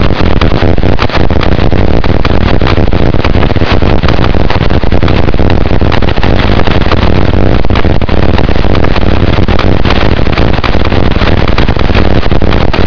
Сигнал РЛС "Воронеж-ДМ"